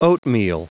Prononciation du mot oatmeal en anglais (fichier audio)
Prononciation du mot : oatmeal